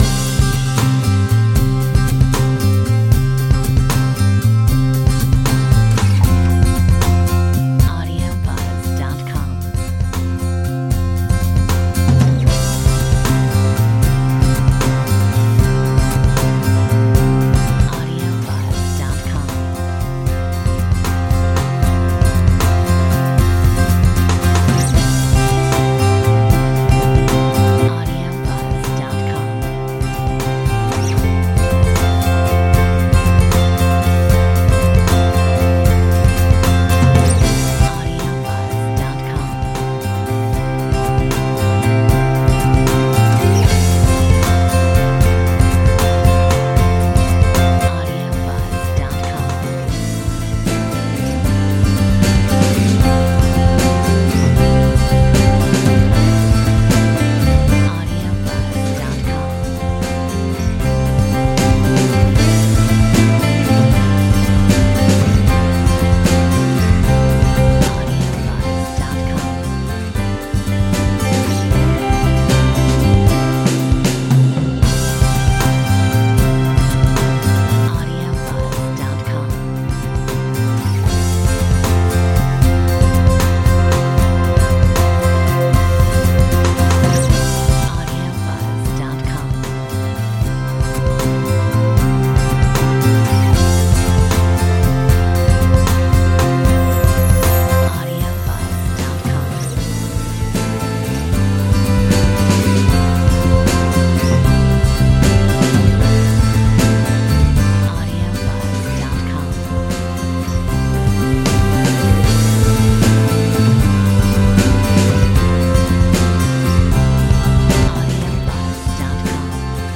Metronome 77